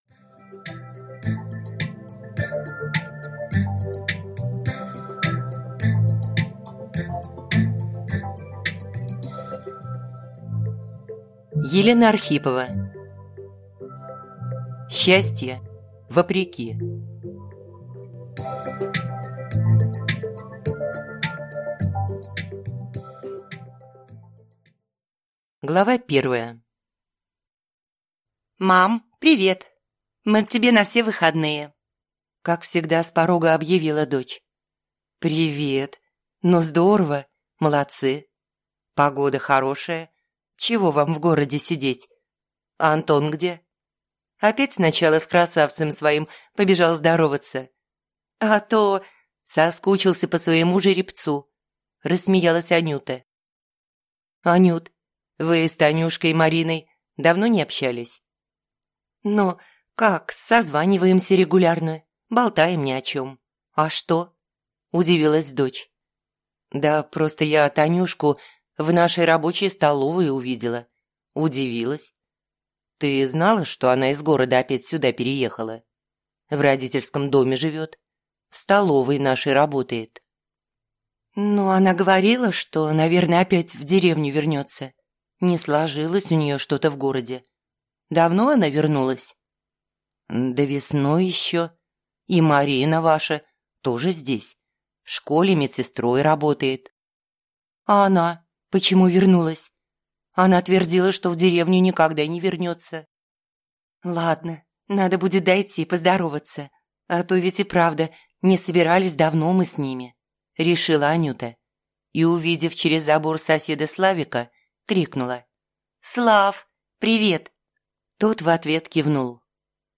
Аудиокнига Счастье вопреки | Библиотека аудиокниг
Прослушать и бесплатно скачать фрагмент аудиокниги